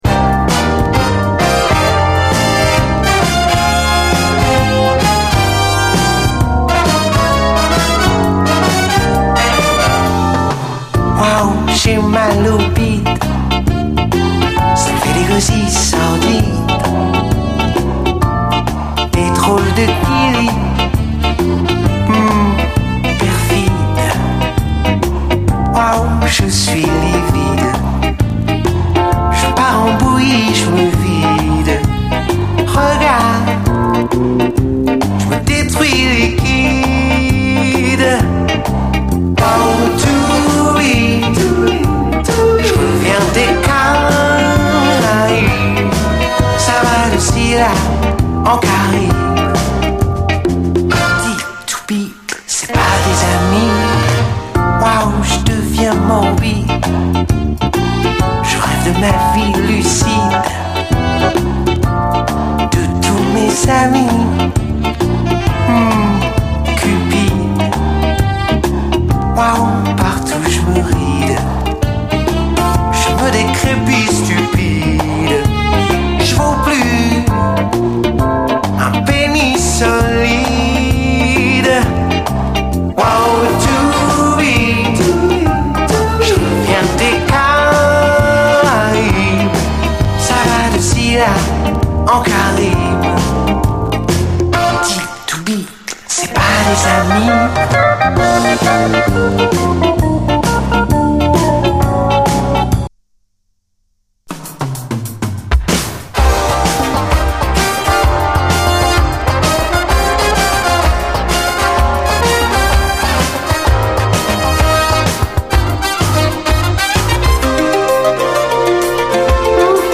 ズンドコ・ビートのレアグルーヴ・トラックで中盤のインスト展開が熱い！